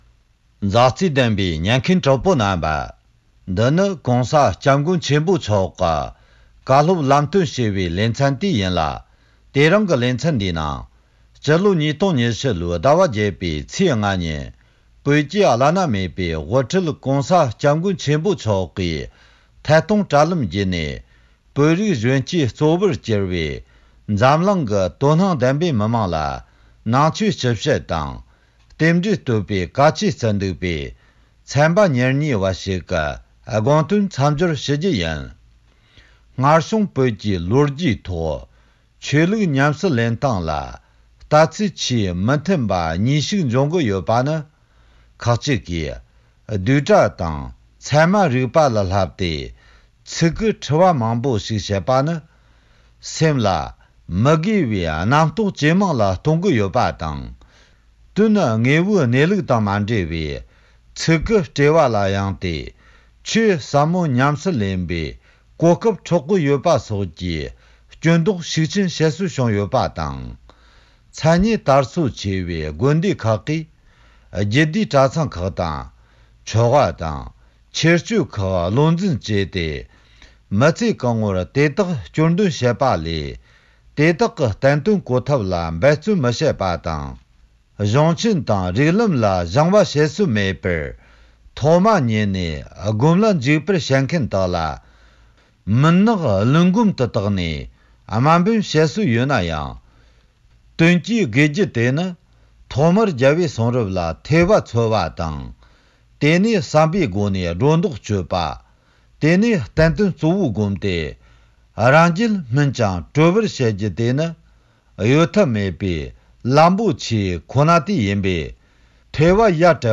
ཕྱི་ལོ་༢༠༢༠ཟླ༨ཚེས་༥ཉིན་བོད་ཀྱི་བླ་ན་མེད་པའི་དབུ་ཁྲིད་སྤྱི་ནོར་༸གོང་ས་༸སྐྱབས་མགོན་ཆེན་པོ་མཆོག་གིས་བོད་རིགས་གཞོན་སྐྱེས་གཙོ་བོར་འགྱུར་བའི་འཛམ་བུ་གླིང་ཁྱོན་ཡོངས་སུ་ཡོད་པའི་དད་ལྡན་པ་རྣམས་ལ་ནང་ཆོས་སྤྱི་བཤད་དང་རྟེན་འབྲེལ་བསྟོད་པའི་བཀའ་ཆོས་བསྩལ་བ་ལས་ཟུར་འདོན་ཞུས་པ་